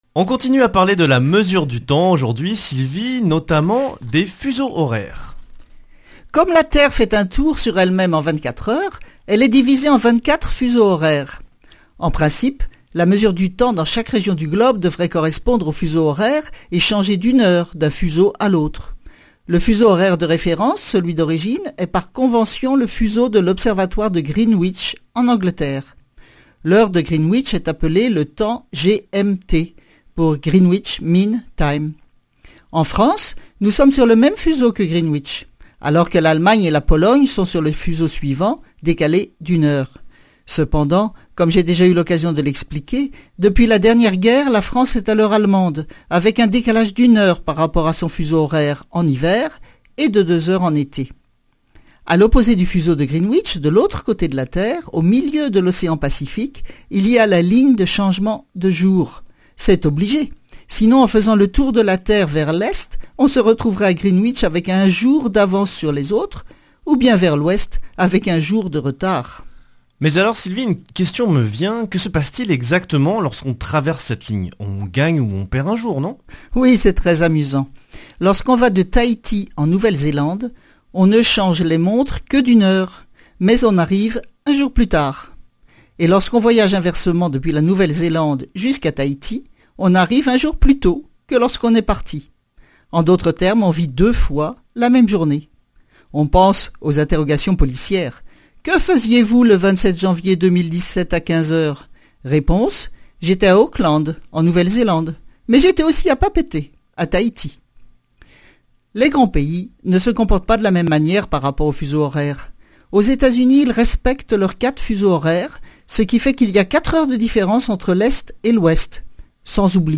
Chronique Astrophysique
Speech